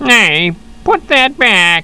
chimp.wav